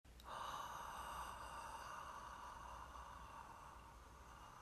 １、先ず、ハーと声を出さず息だけを吐きます。
ハーーーーーーー